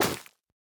Minecraft Version Minecraft Version snapshot Latest Release | Latest Snapshot snapshot / assets / minecraft / sounds / block / netherwart / break4.ogg Compare With Compare With Latest Release | Latest Snapshot